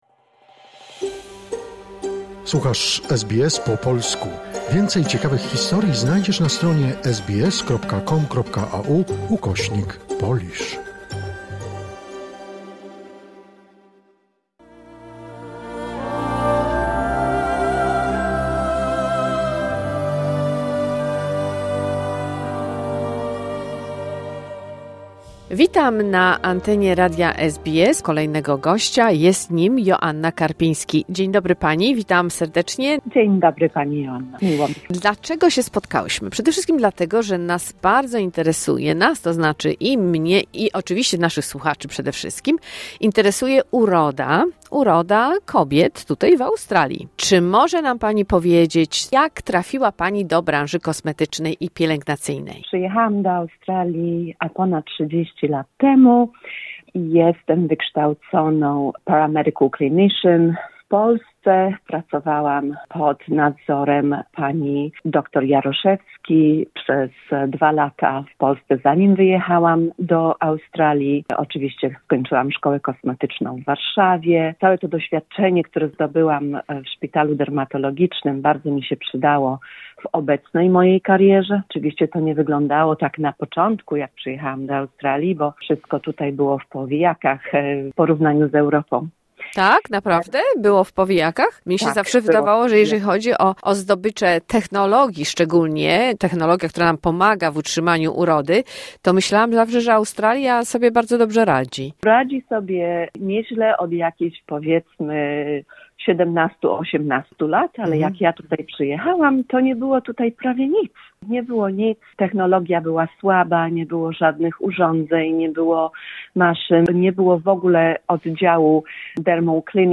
Pierwsza część rozmowy